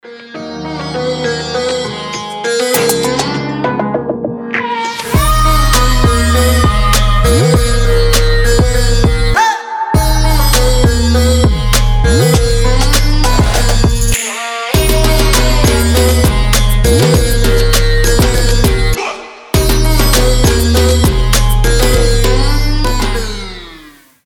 • Качество: 320, Stereo
EDM
мощные басы
Trap
Midtempo
индийские мотивы